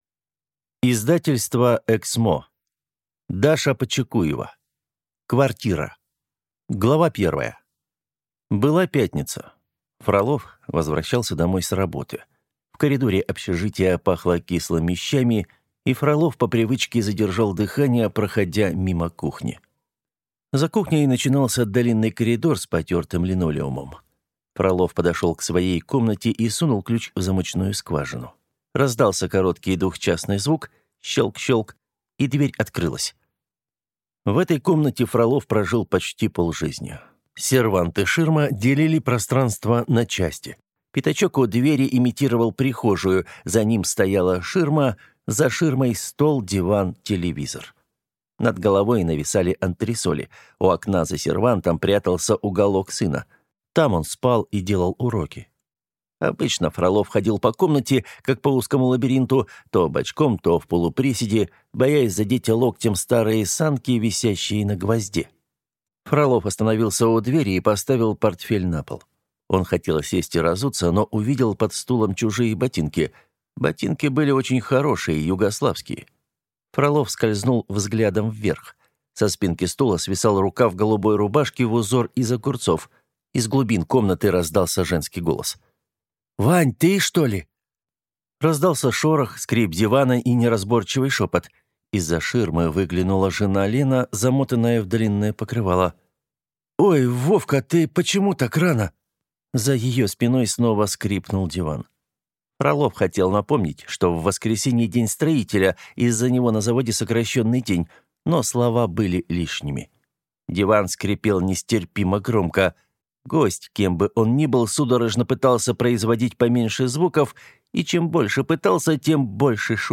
Аудиокнига Квартира | Библиотека аудиокниг